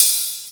OpenHH SP12X.wav